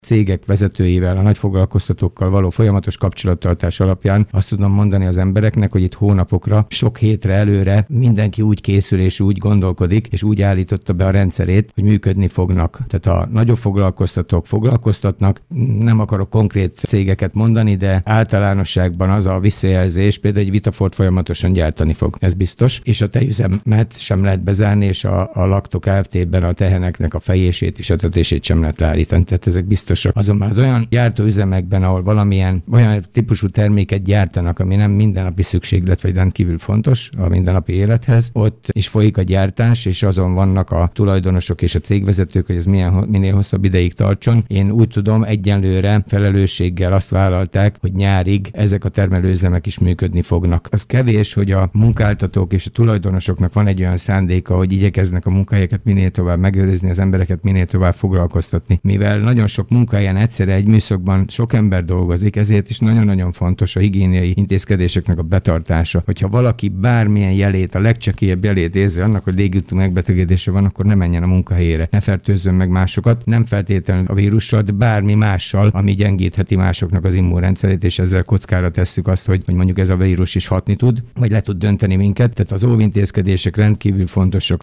Kőszegi Zoltán, Dabas Város polgármestere beszélt arról, hol tartanak a cégekkel való egyeztetések.